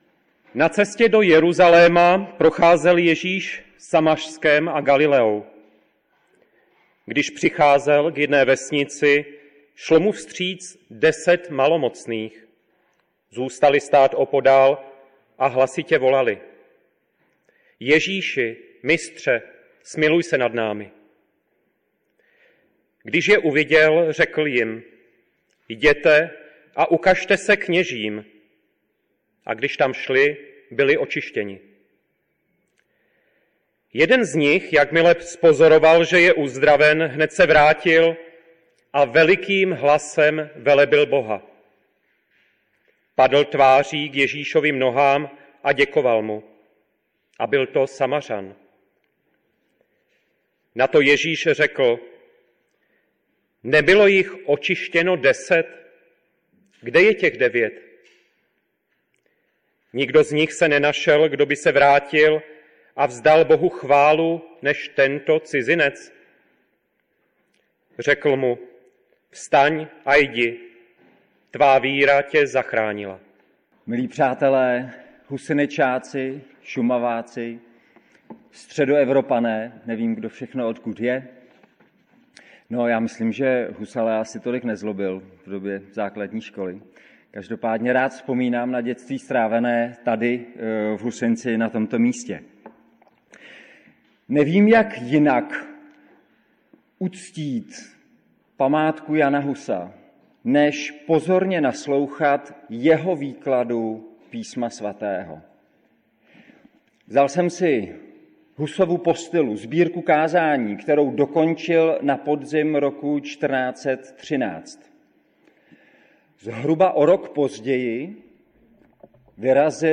Ekumenická bohoslužba 2025